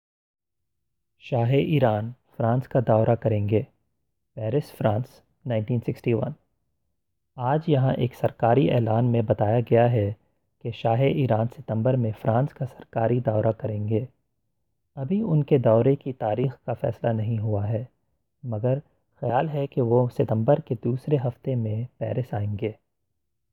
Once you feel you know the words, the second audio recording will be only in Urdu without any English translations.